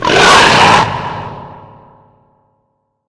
Divergent / mods / Soundscape Overhaul / gamedata / sounds / monsters / lurker / hit_4.ogg
hit_4.ogg